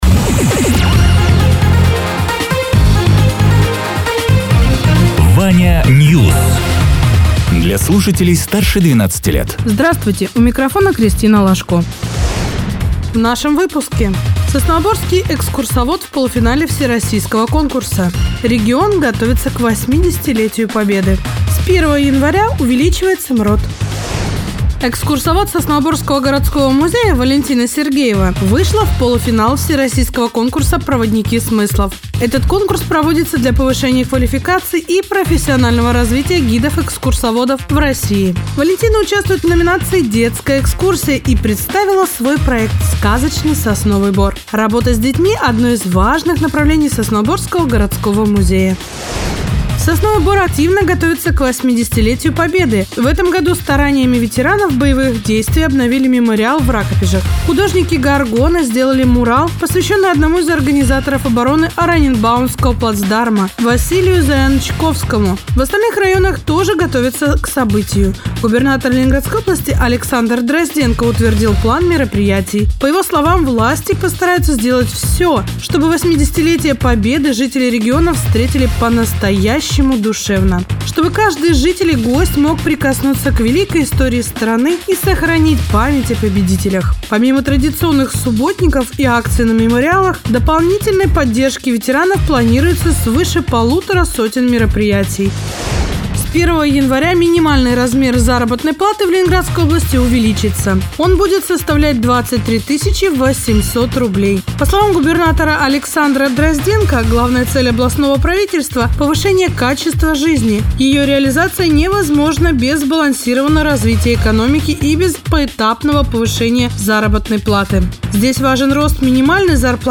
Радио ТЕРА 19.11.2024_08.00_Новости_Соснового_Бора